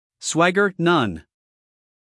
英音/ ˈswæɡə(r) / 美音/ ˈswæɡər /